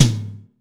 ROOM TOM2A.wav